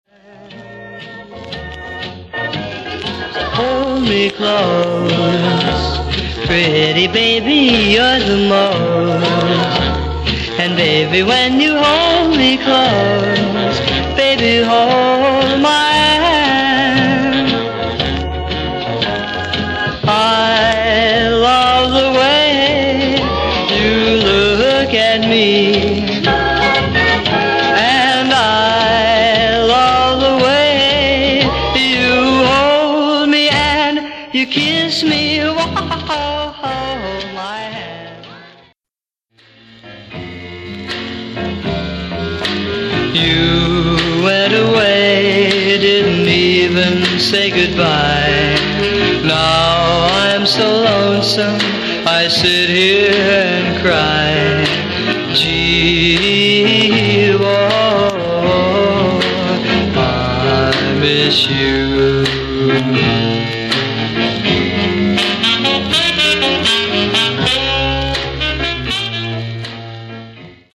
Genre: Rockabilly/Early R&R
Teener R&R b/w slowy ballads.
sax